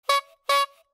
Звуки клоунов
Звук гудка клоуна при нажатии на нос